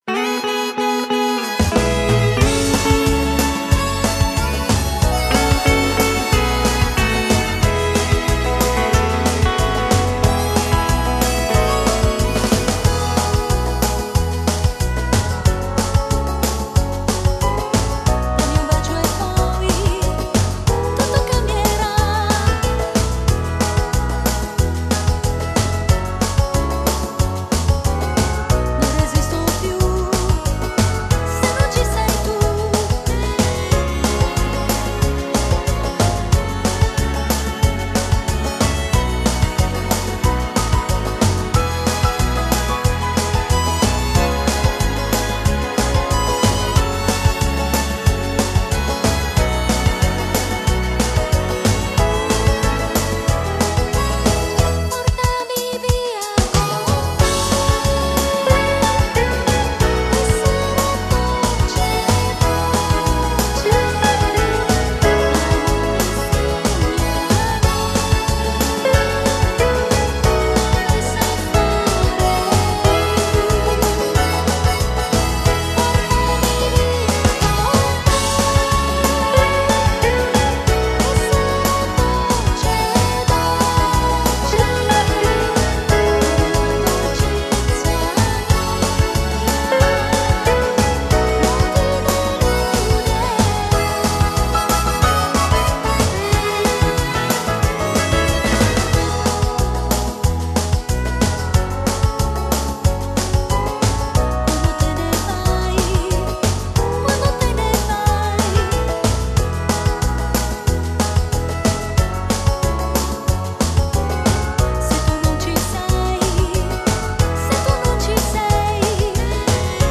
Genere: Moderato
Scarica la Base Mp3 (3,09 MB)